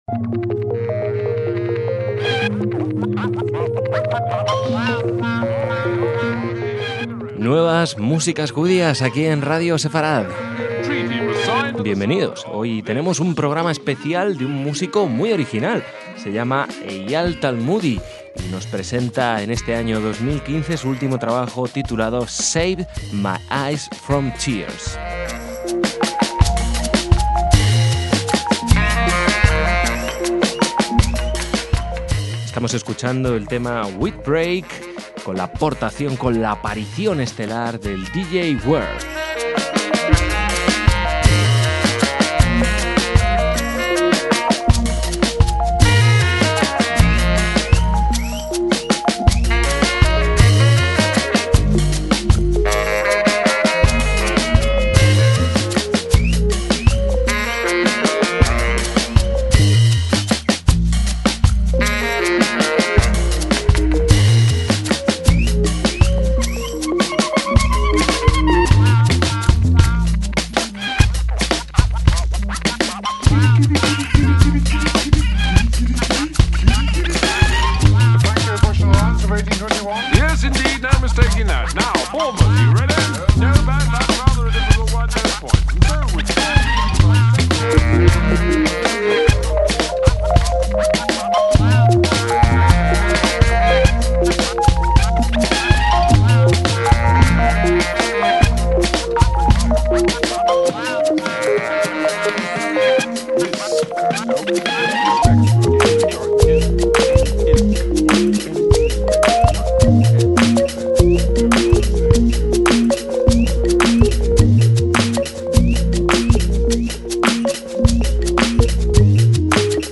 Un estilo de trabajo casi a través de internet que ha dado como resultado un soul electrónico experimental de gran calidad y lirismo, alejado de la fiesta constante de sus anteriores bandas.